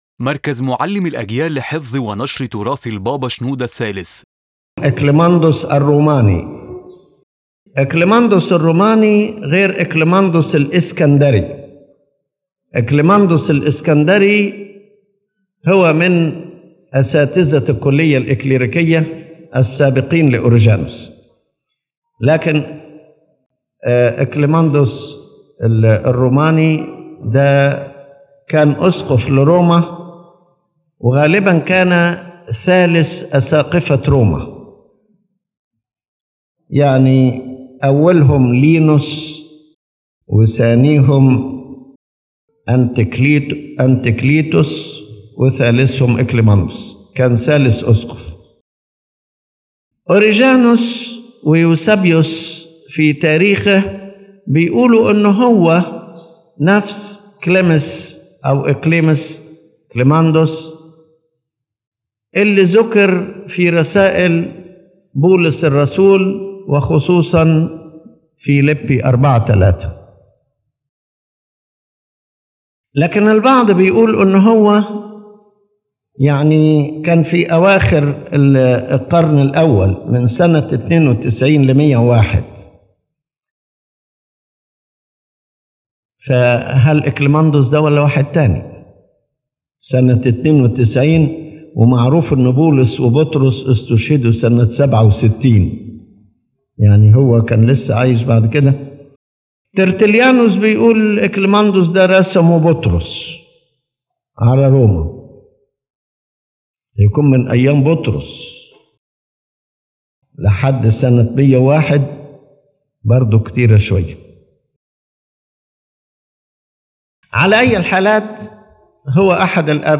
His Holiness Pope Shenouda III presents a narration and commentary on texts of one of the Fathers of the Church (Clement of Rome) aiming to show the essence of his spiritual and practical teachings. The lecture focuses on a letter sent to the people of Corinth and addresses issues of division, envy, and repentance, with references to biblical and historical examples.